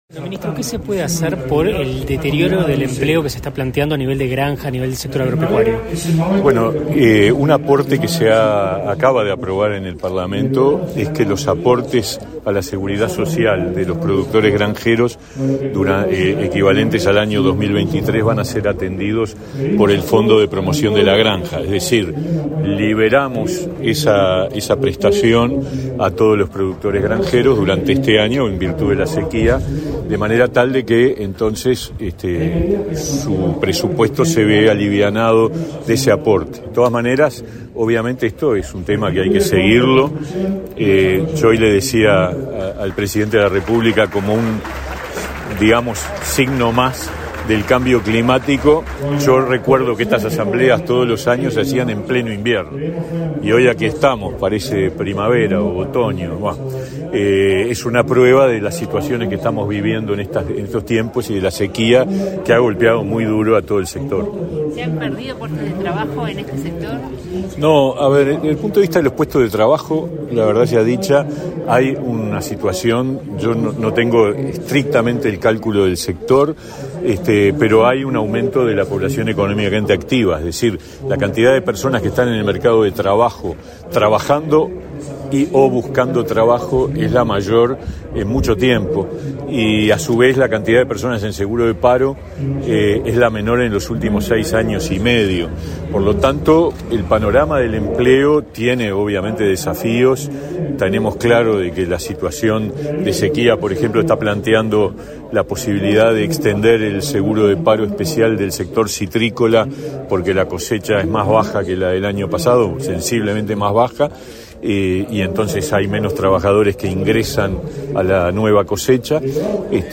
Declaraciones a la prensa del ministro de Trabajo y Seguridad Social, Pablo Mieres
Declaraciones a la prensa del ministro de Trabajo y Seguridad Social, Pablo Mieres 05/06/2023 Compartir Facebook Twitter Copiar enlace WhatsApp LinkedIn Tras participar en la 92a Asamblea Anual de la Sociedad Fomento y Defensa Agraria, evento en el que participó el presidente de la República, Luis Lacalle Pou, este 4 de junio, el ministro Pablo Mieres realizó declaraciones a la prensa.